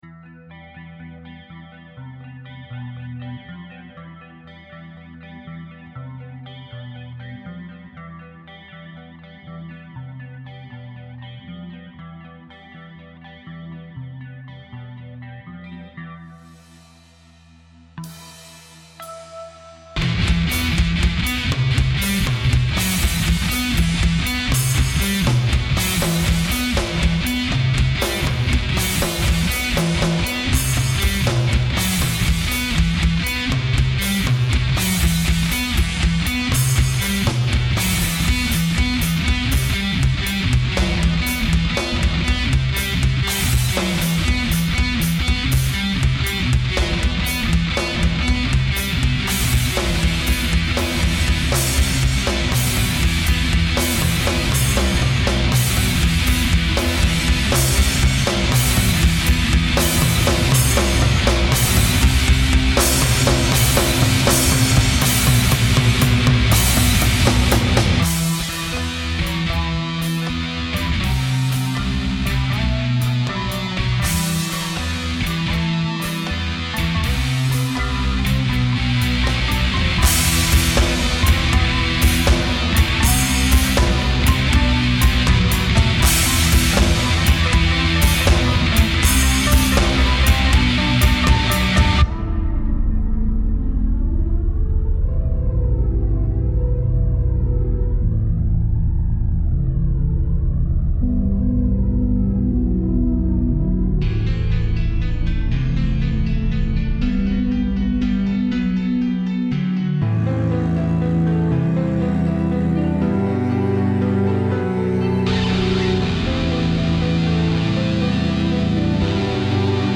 Morceau Instrumental metalloïde .
Au niveau mélodique et harmonique c'est bien.
La batterie etl e son des grattes saturées ne sont pas top.
Batterie : C'est des samples du NS-Kit avec le sampler de Tracktion.
C'est vrai qu'en satu ca grésille, je devrais sans doute modifier ça...